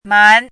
怎么读
mái
mán